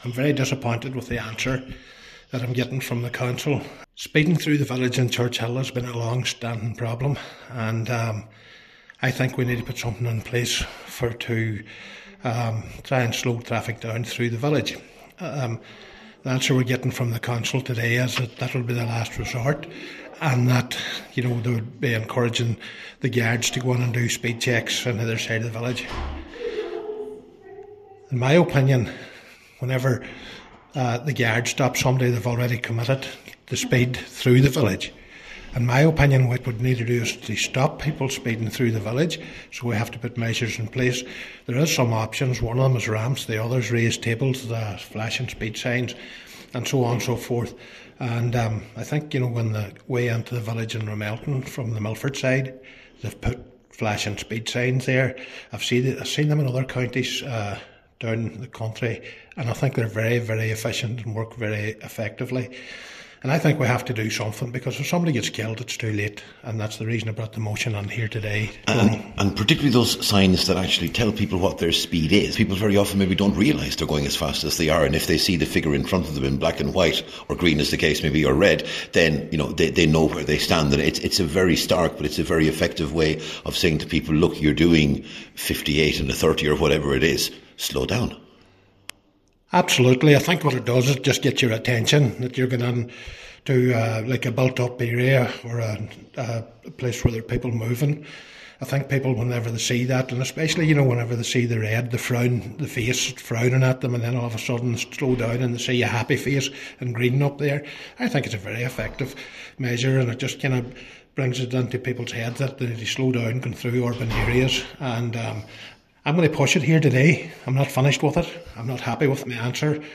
Cllr McBride says that is not enough: